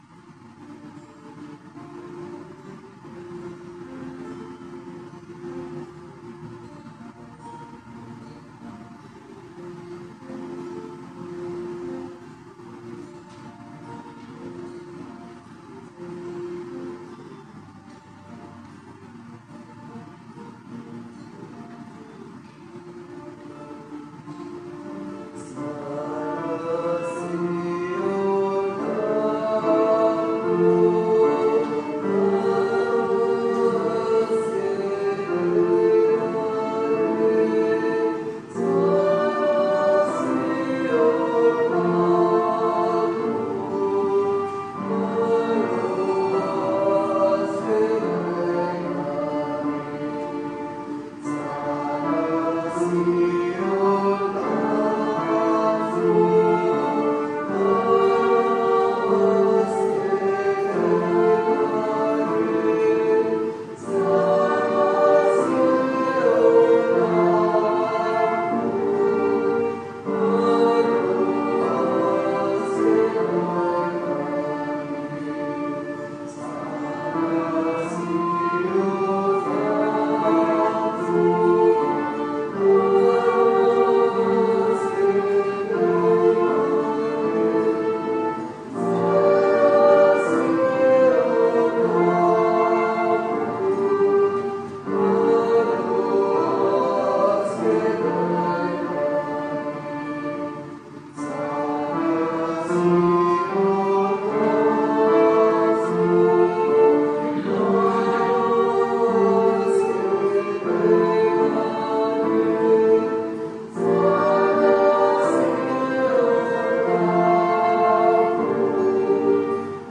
Pregària de Taizé
Parròquia de la Sagrada Família - Diumenge 28 de juny de 2015